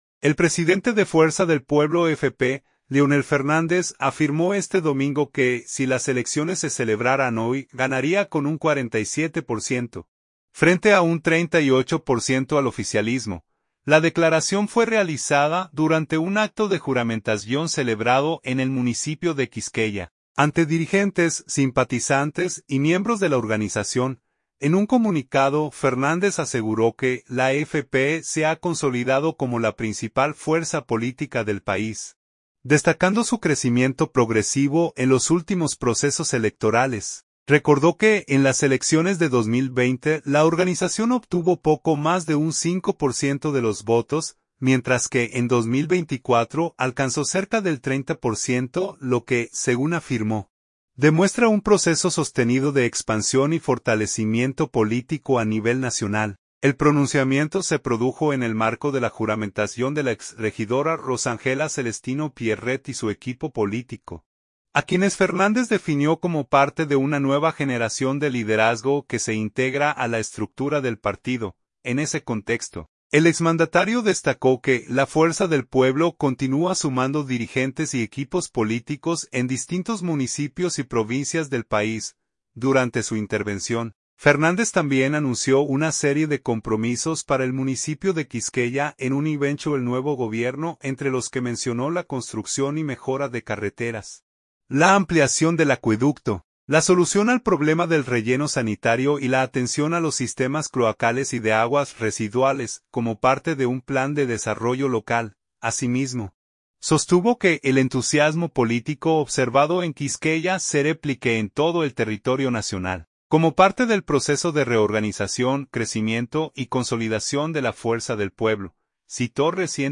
La declaración fue realizada durante un acto de juramentación celebrado en el municipio de Quisqueya, ante dirigentes, simpatizantes y miembros de la organización.